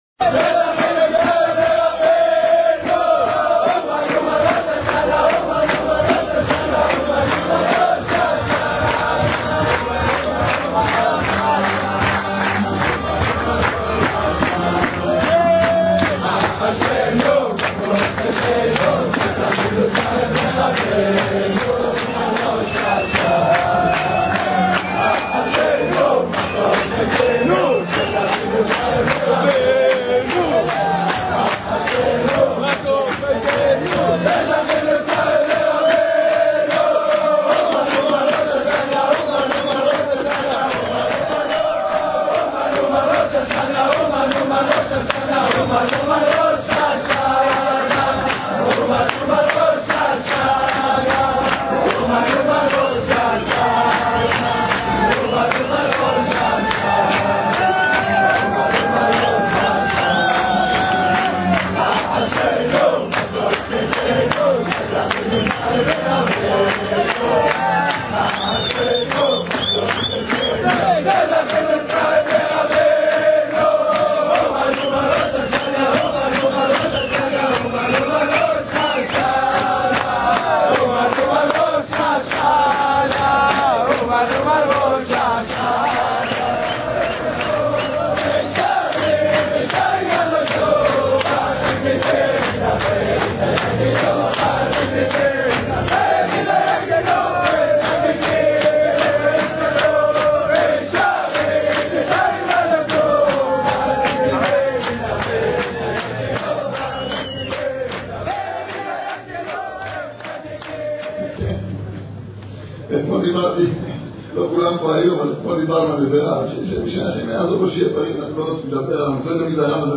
אומן ראש השנה תשעב 2011- שיעור שני (בקלויז)